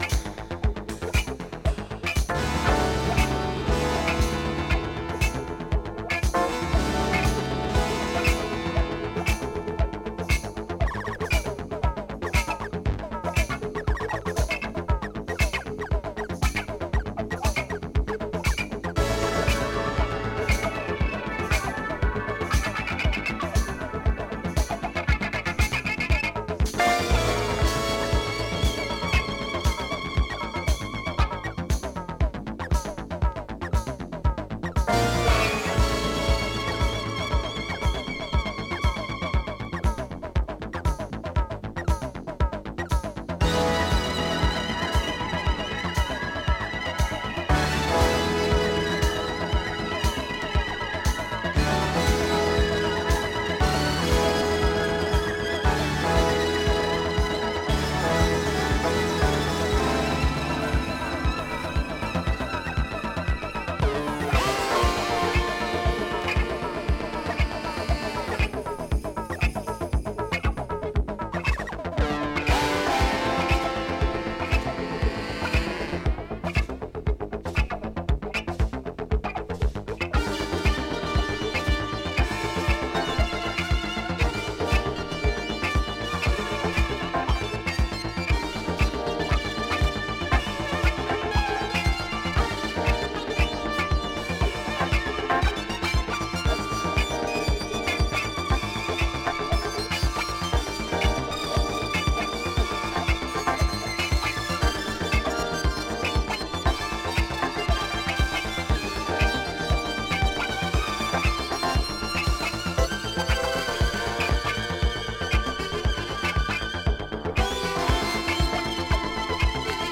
este tesouro da música Disco à atenção
essencialmente instrumental